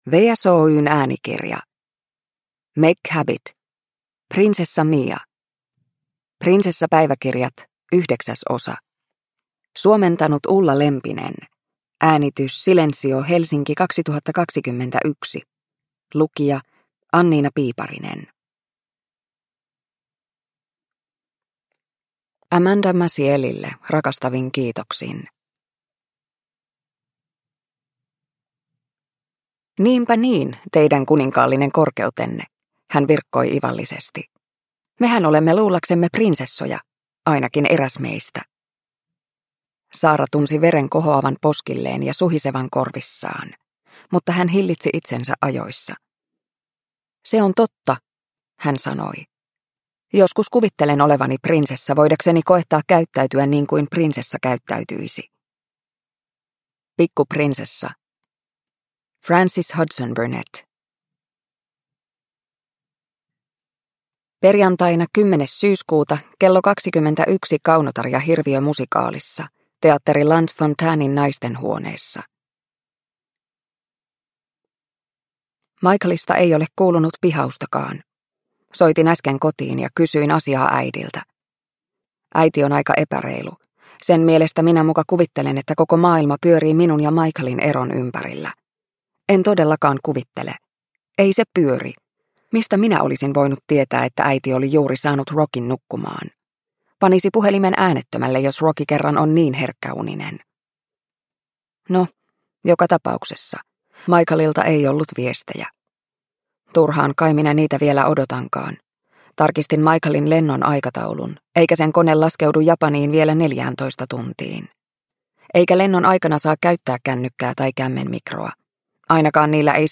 Prinsessa Mia – Ljudbok – Laddas ner